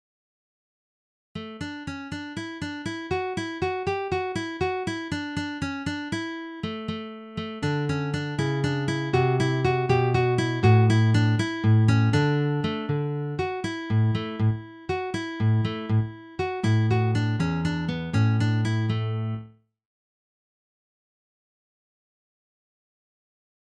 Sololiteratur
Gitarre (1)